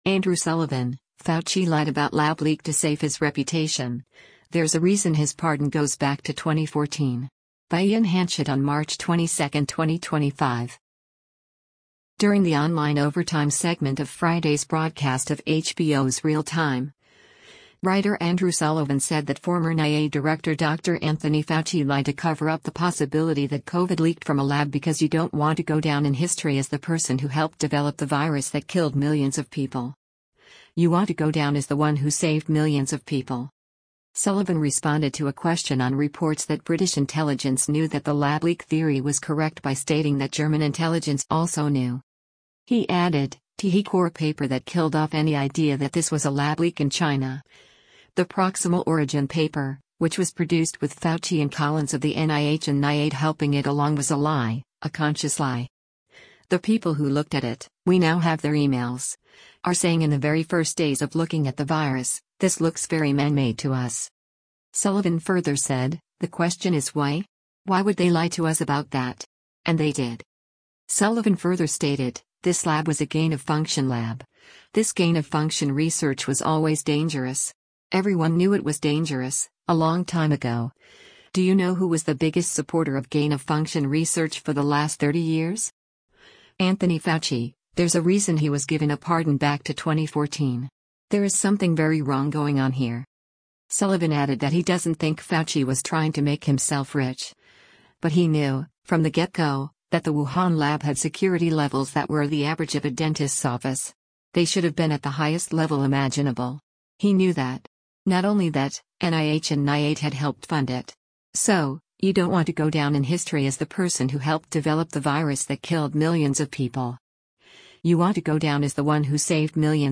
During the online “Overtime” segment of Friday’s broadcast of HBO’s “Real Time,” writer Andrew Sullivan said that former NIAID Director Dr. Anthony Fauci lied to cover up the possibility that COVID leaked from a lab because “you don’t want to go down in history as the person who helped develop the virus that killed millions of people. You want to go down as the one who saved millions of people.”